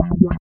88 BS LICK-L.wav